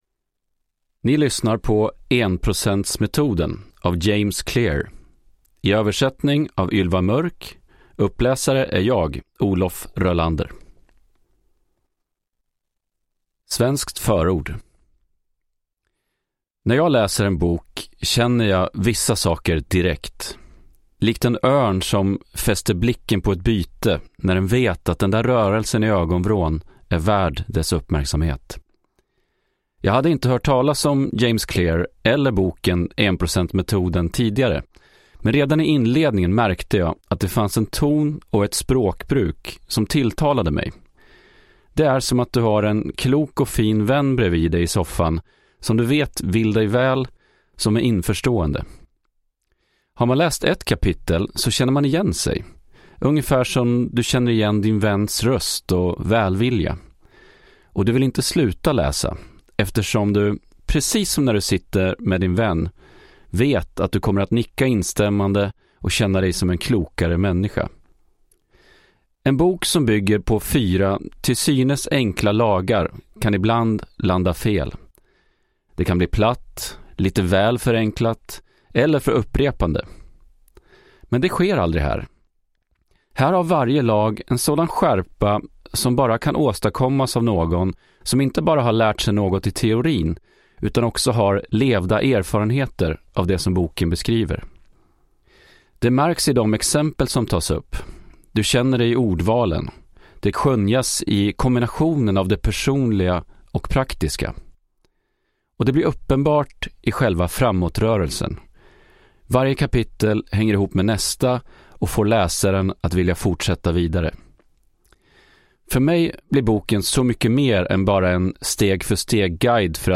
1 %-metoden : små förändringar, stora resultat : ett enkelt och beprövat sätt att skapa goda vanor och bryta dåliga (ljudbok) av James Clear